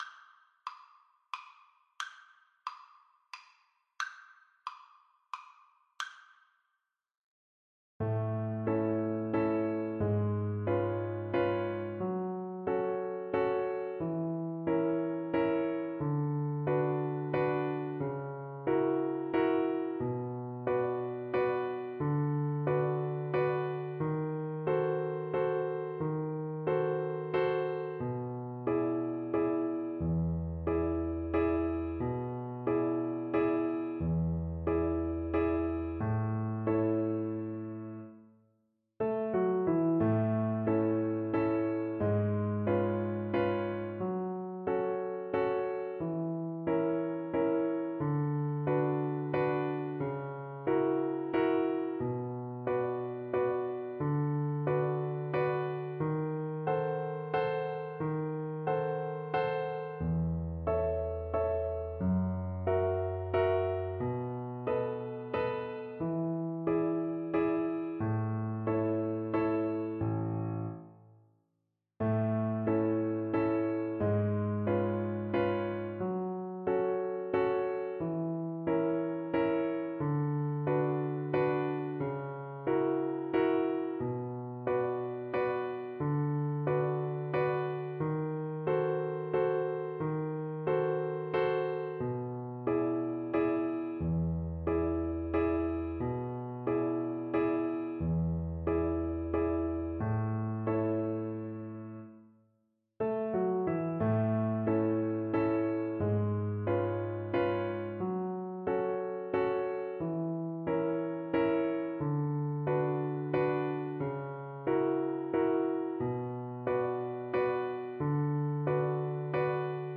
Free Sheet music for Violin
Violin
A major (Sounding Pitch) (View more A major Music for Violin )
[Waltz, one in a bar] = 140
3/4 (View more 3/4 Music)
Classical (View more Classical Violin Music)
Brazilian